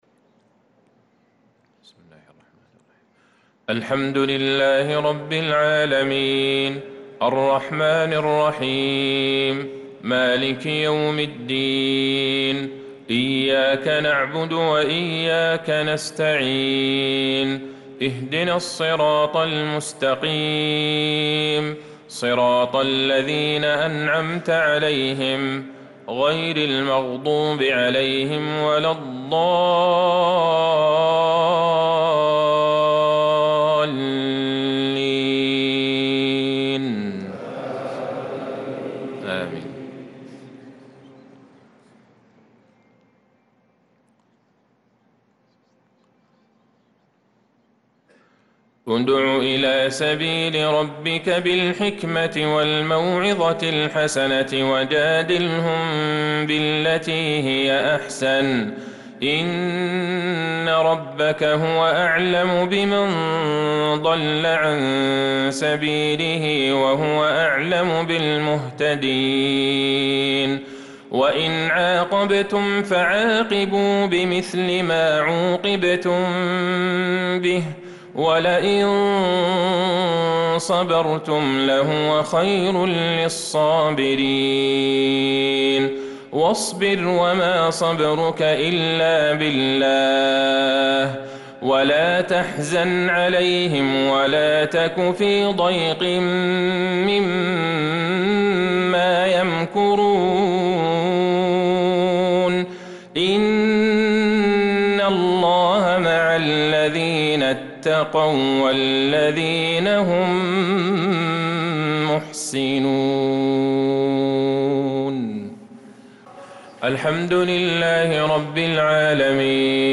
صلاة العشاء للقارئ عبدالله البعيجان 23 ذو القعدة 1445 هـ